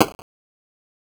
scavengers_chop1.aif